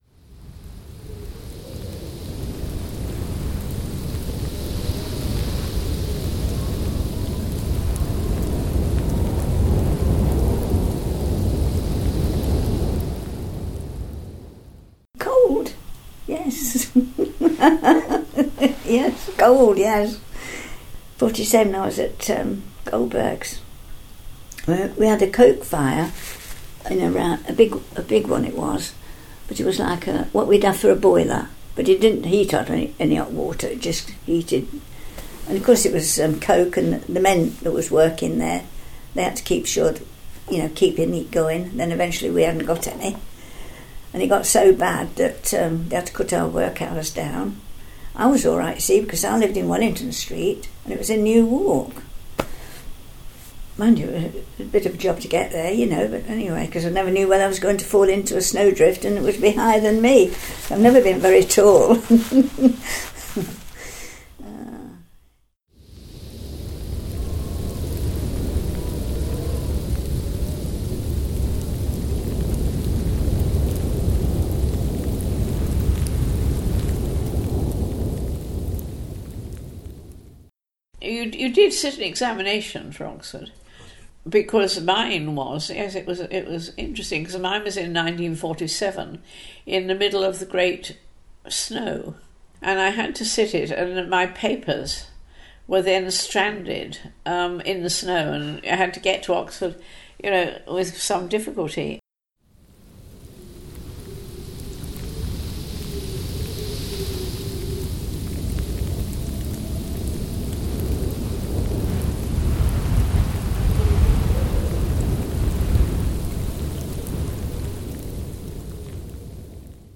A montage of sound clips and stories which describe the extreme weather conditions of 1947’s winter.